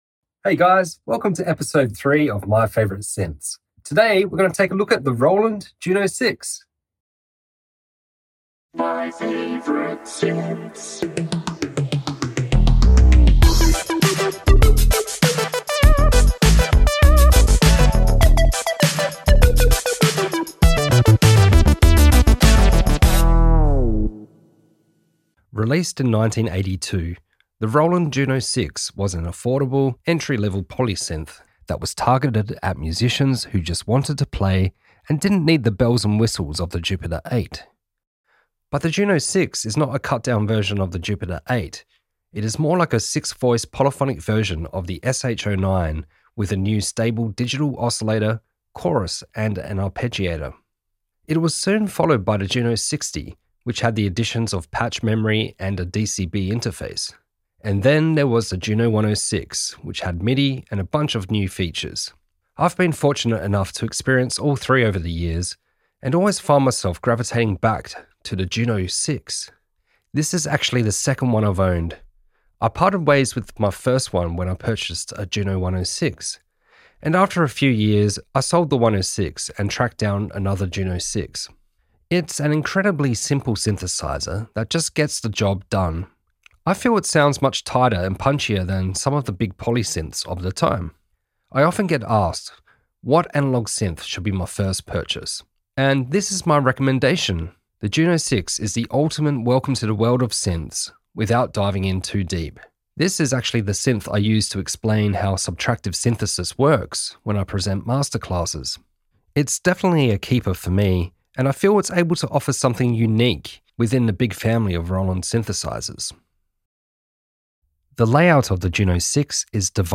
Episode 3 of "My Favourite Synths" - the Roland JUNO-6 Released in 1982, the Roland Juno 6 was an affordable, entry level Poly Synth that was targeted at musicians who just wanted to play and didn’t need all the bells and whistles of the Jupiter 8. It is an extremely simple Synthesizer, aesthetically pleasing and has a magical sound.